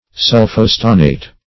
Sulphostannate \Sul`pho*stan"nate\